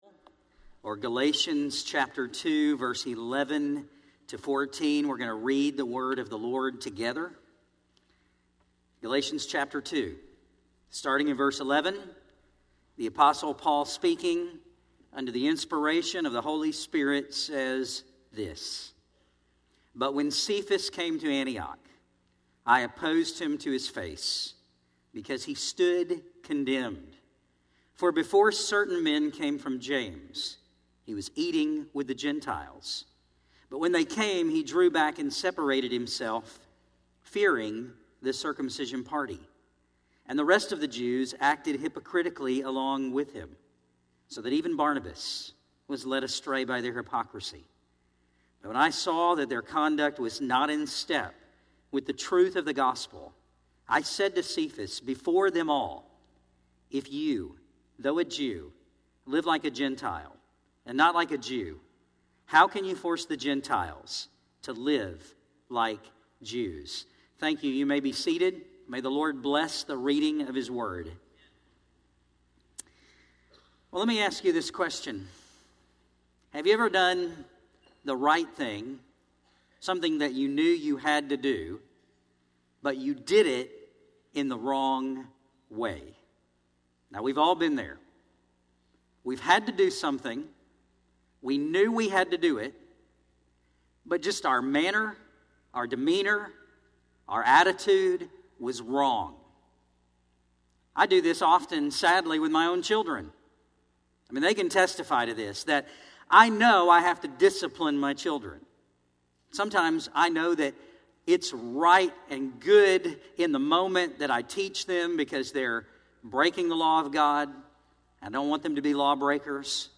Philippians Study September